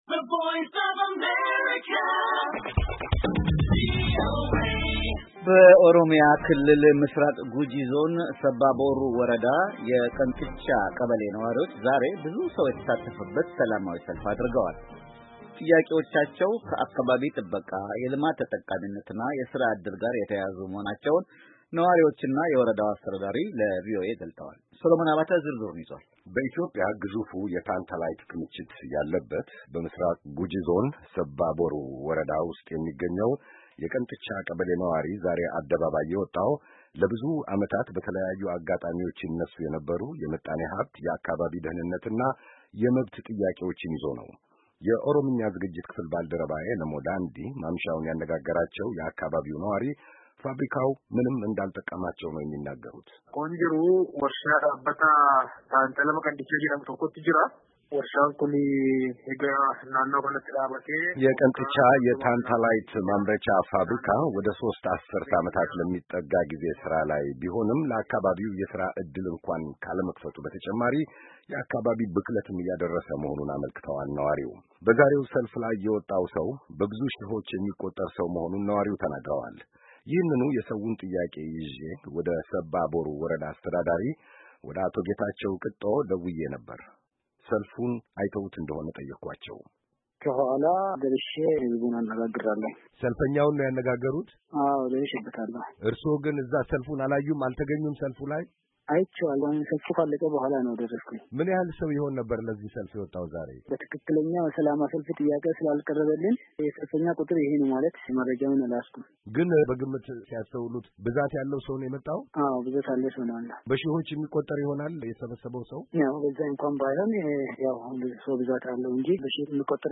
የቀንጥቻ የታንታላይት፣ ቦሮማይትና ኳርትዝ ማምረቻ ፋብሪካ ወደ ሦስት አሠርት ዓመታት ለሚጠጋ ጊዜ ሥራ ላይ ቢሆንም ለአካባቢው የሥራ ዕድል እንኳ ካለመክፈቱ በተጨማሪ የአካባቢ ብክለትም እያደረሰ መሆኑን በሰልፉ ላይ የነበሩ አንድ የአካባቢው ነዋሪ አመልክተዋል።
የቦሩ ሰባ ወረዳ አስተዳዳሪ አቶ ጌታቸው ቅጦ ከቪኦኤ ጋር ባደረጉት ቃለ-ምልልስ የሰዉን ጥያቄ ከሚመለከታቸው ባለሥልጣናት ጋር እየመከሩበት እንደሆነ ተናግረዋል።